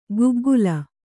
♪ guggula